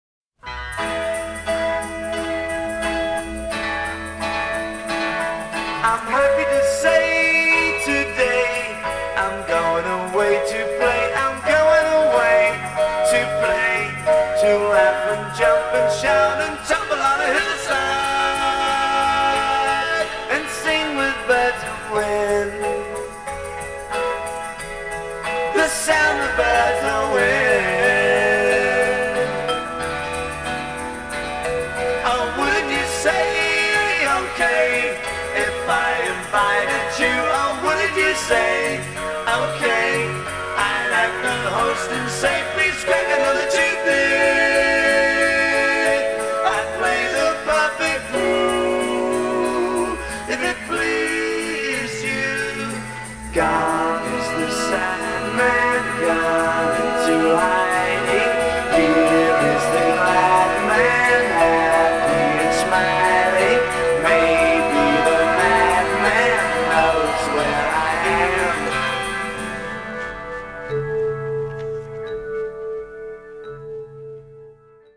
Listen particularly to the segments with backing vocals.